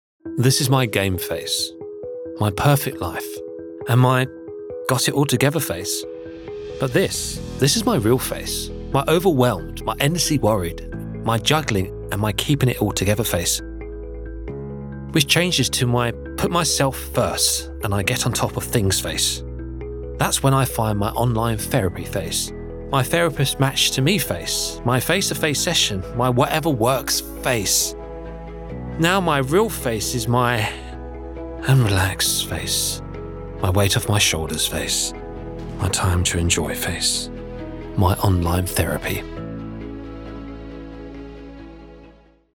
Mon accent est celui de l'estuaire, mais la plupart me connaissent comme un gars de l'Essex.
Baryton